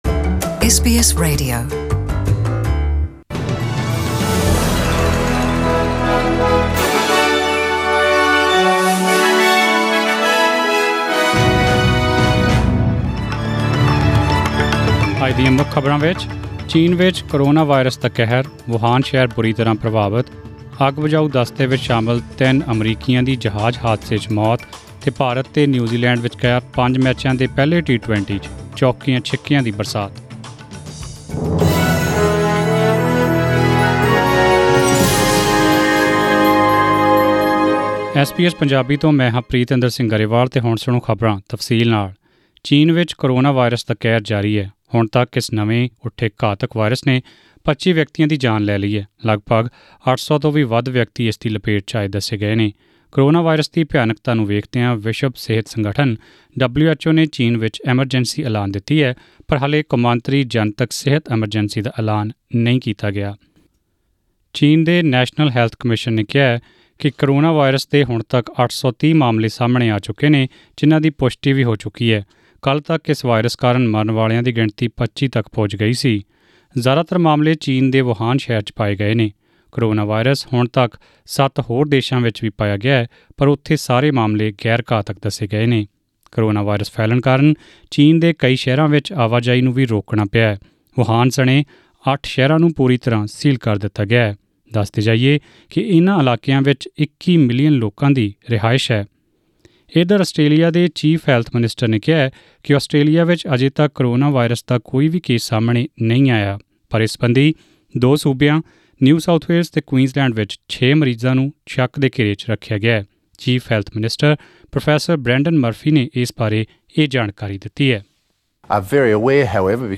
Australian News in Punjabi: 24 January 2020